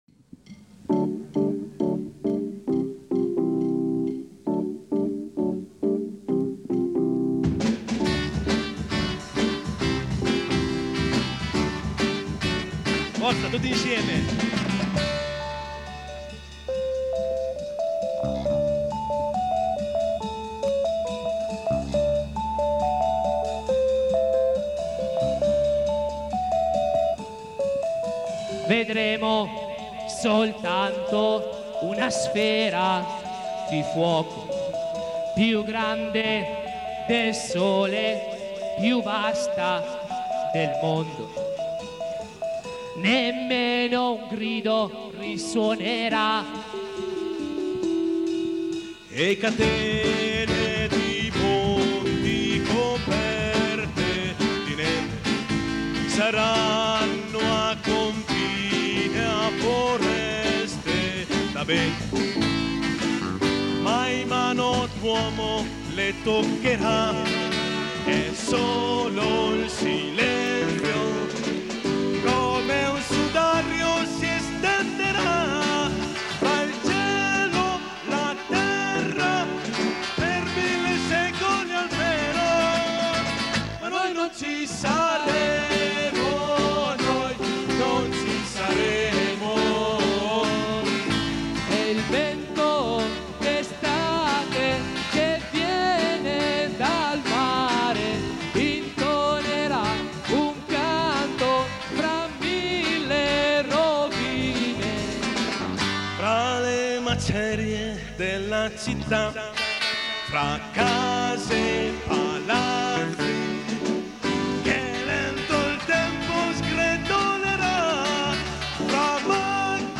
Download   CD live (1983-1993) del 1998